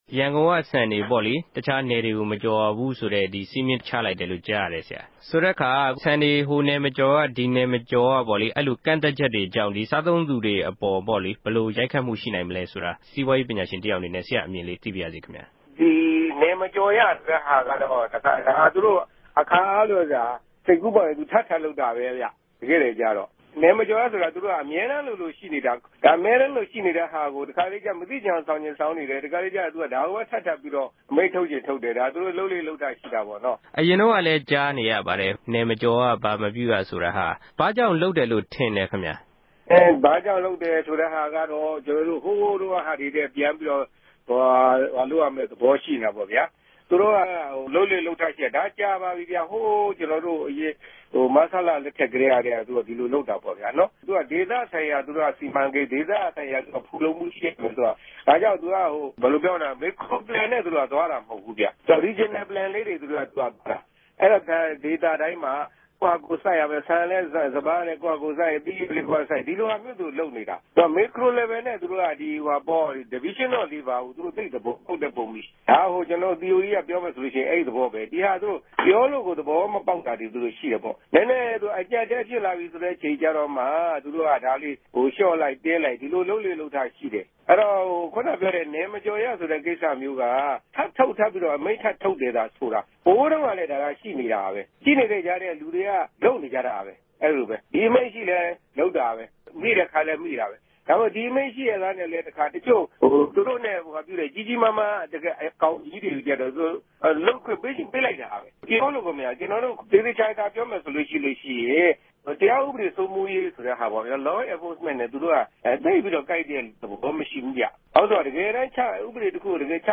စီးပြားရေးပညာရြငိံြင့် ဆက်သြယ်မေးူမန်းခဵက်။